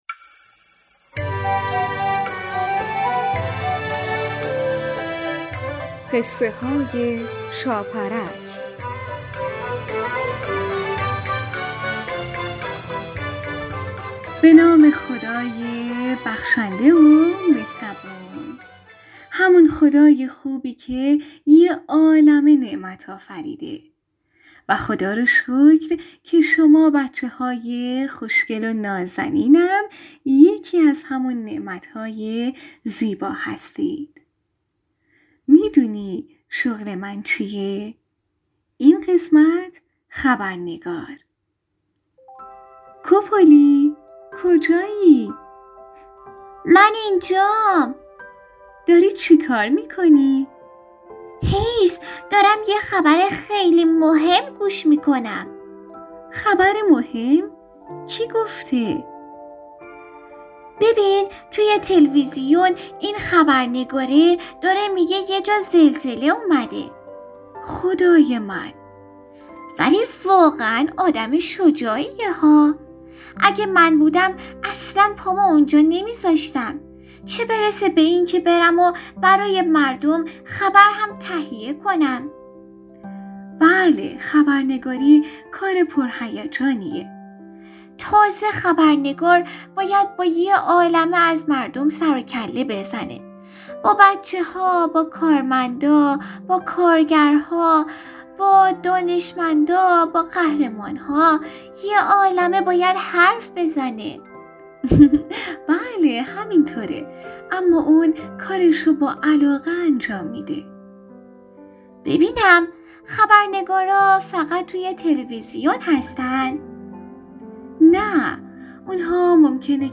قسمت صد و سیزدهم برنامه رادیویی قصه های شاپرک با نام خبرنگار یک داستان کودکانه مذهبی با موضوعیت نماز و اهمیت آن